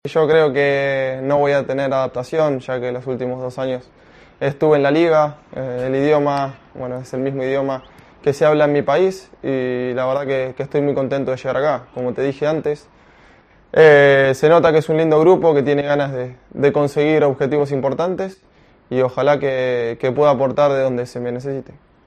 AUDIO: Primeres paraules de l'últim fitxatge del RCDE Espanyol, Jonathan Calleri